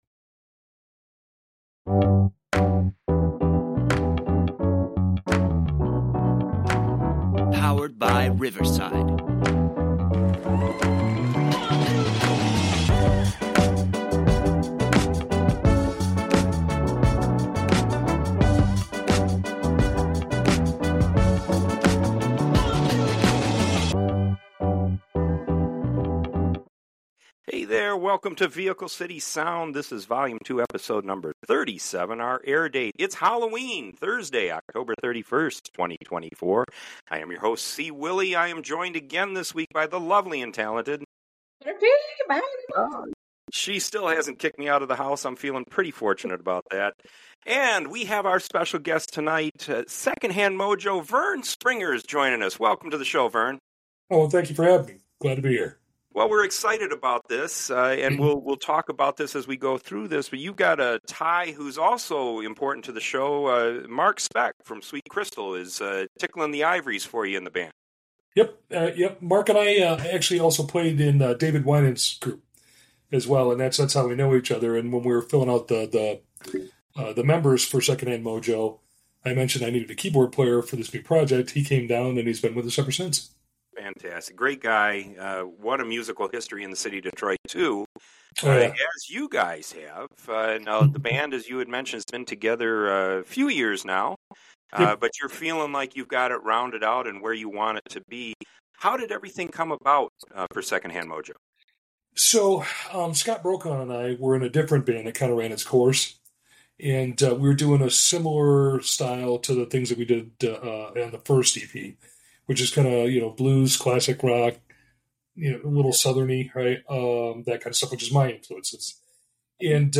Second Hand Mojo is uniquely talented at taking the sounds we all grew to love in the 70s, 80s and 90s then combining them into new songs we'd all deem hits today!&nbsp